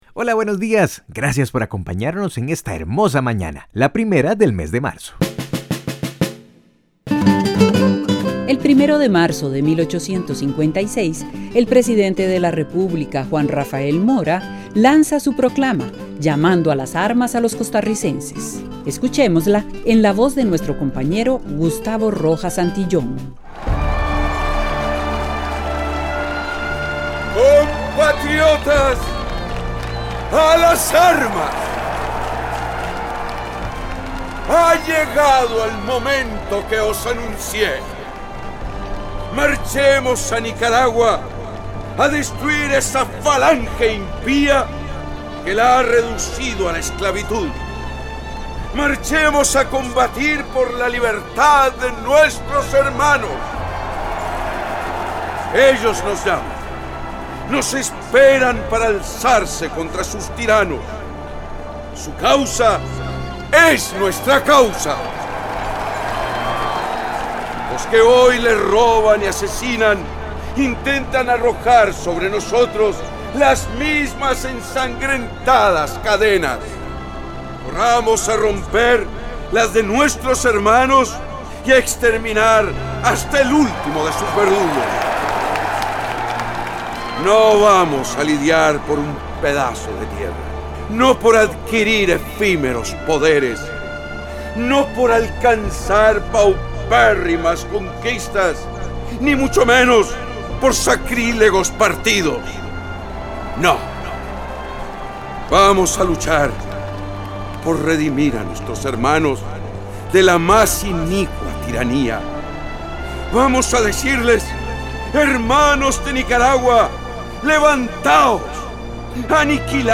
escuchémosla en la voz de nuestro compañero